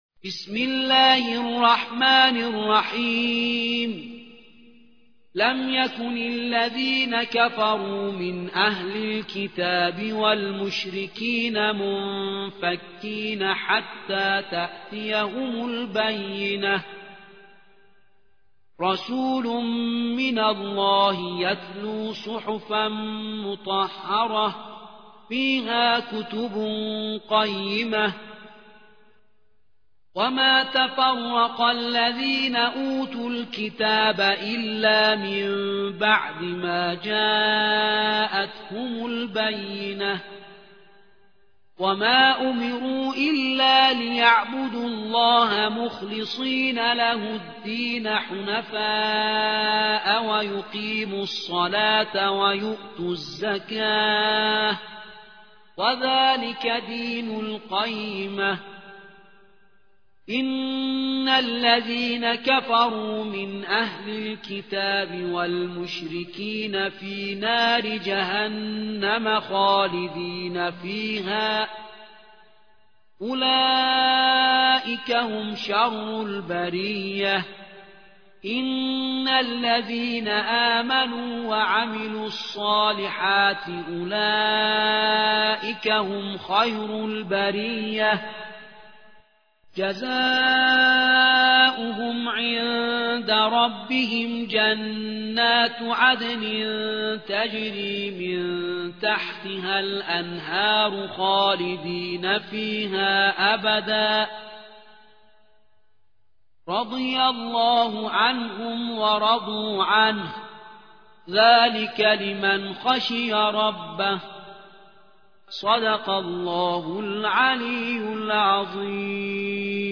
98. سورة البينة / القارئ